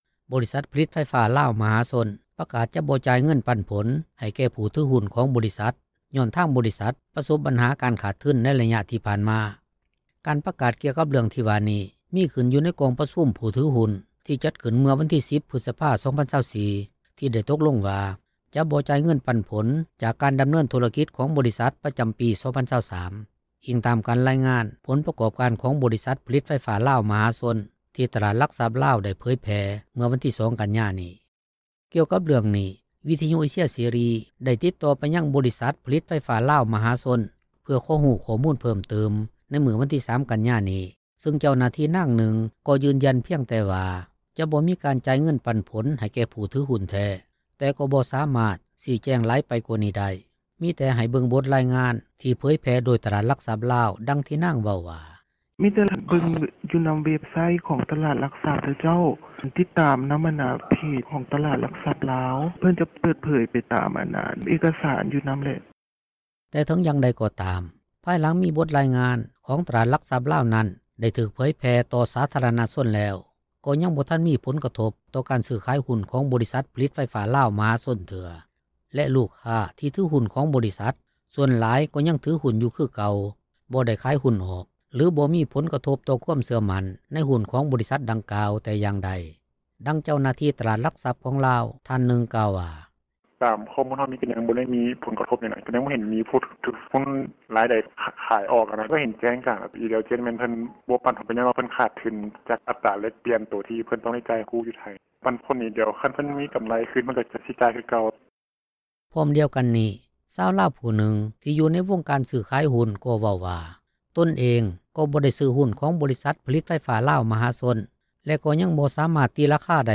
ກ່ຽວກັບເລື້ອງນີ້ ວິທຍຸເອເຊັຽເສຣີ ໄດ້ຕິດຕໍ່ໄປຍັງ ບໍລິສັດຜະລິດໄຟຟ້າລາວມະຫາຊົນ ເພື່ອຂໍຮູ້ຂໍ້ມູນເພີ້ມຕື່ມ ໃນມື້ວັນທີ 3 ກັນຍານີ້ ຊຶ່ງເຈົ້າໜ້າທີ່ນາງນຶ່ງ ກໍຢືນຢັນພຽງແຕ່ວ່າ ຈະບໍ່ມີການຈ່າຍເງິນປັນຜົນ ໃຫ້ແກ່ຜູ້ຖືຮຸ້ນແທ້ ແລະກໍບໍ່ສາມາດ ຊີ້ແຈງຫລາຍໄປກວ່ານີ້ໄດ້, ມີແຕ່ໃຫ້ເບິ່ງ ບົດລາຍງານ ທີ່ເຜີຍແຜ່ໂດຍຕະຫລາດຫລັກຊັບລາວ ດັ່ງທີ່ນາງເວົ້າວ່າ: